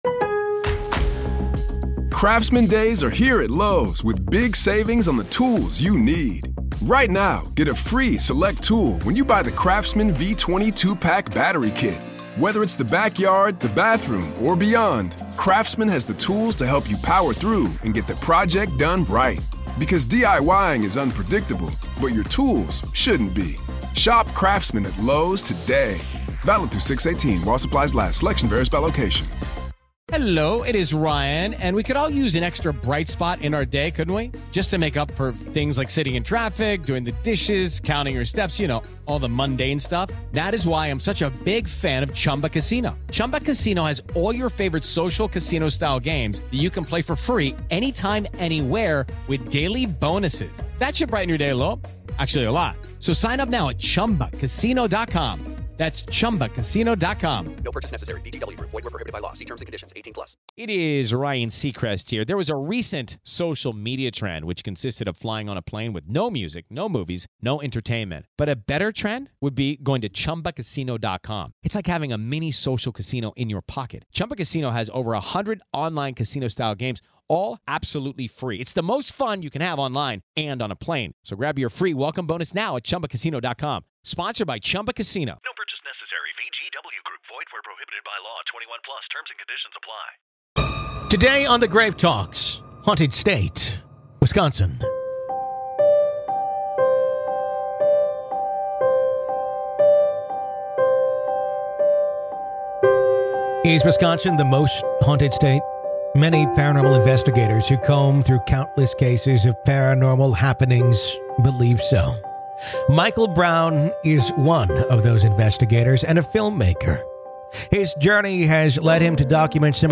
How Haunted Is Wisconsin? | Haunted State Interview